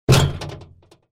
Звуки банкомата